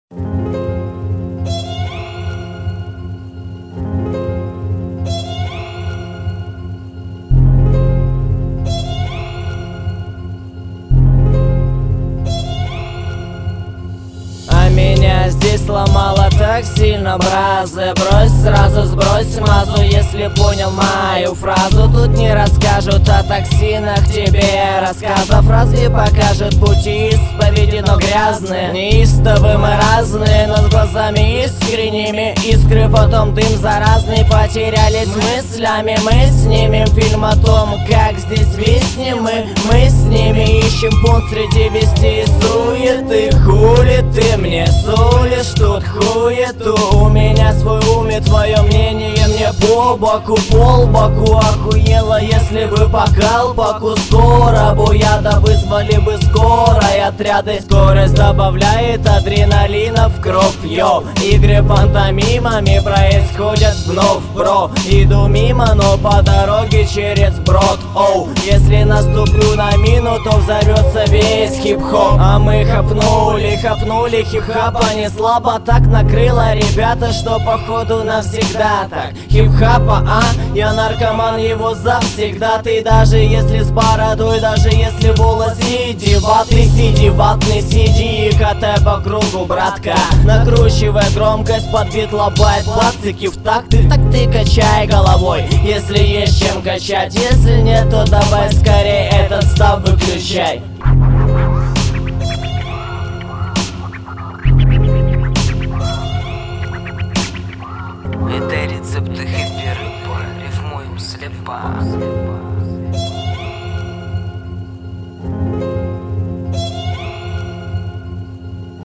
• Category: The audio fairy tale "A kitten named Woof"